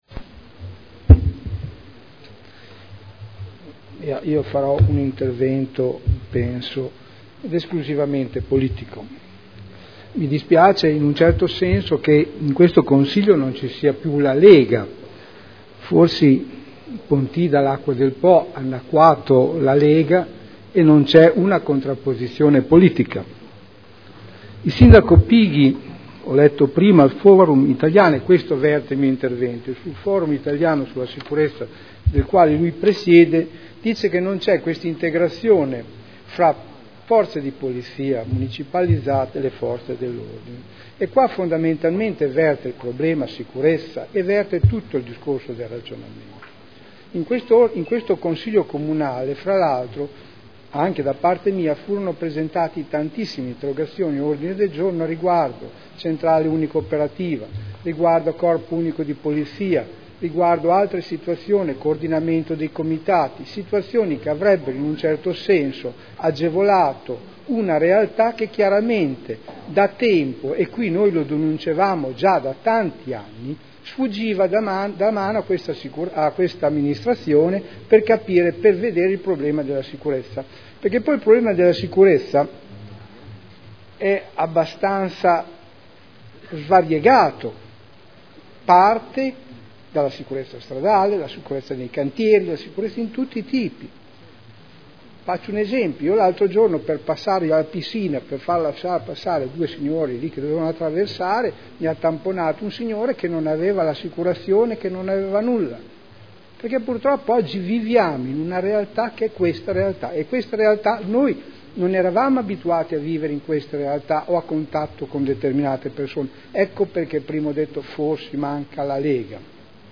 Seduta del 08/04/2013 Dibattito. Report Polizia Municipale.